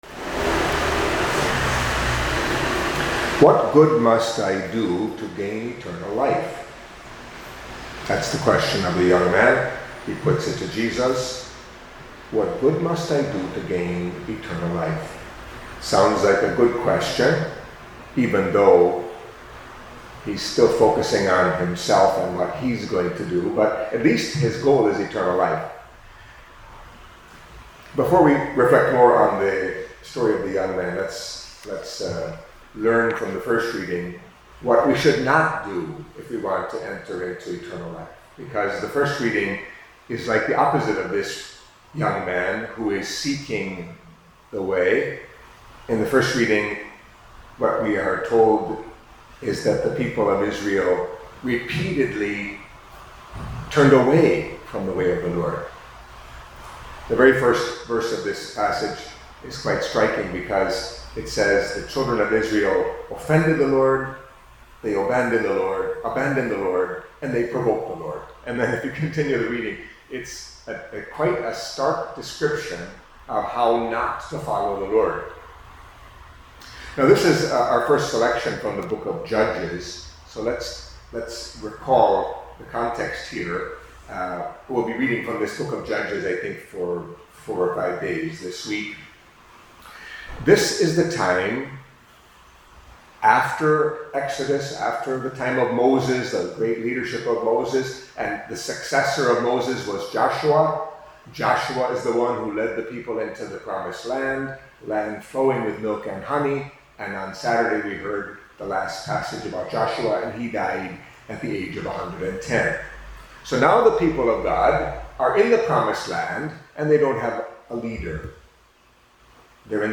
Catholic Mass homily for Monday of the Twentieth Week in Ordinary Time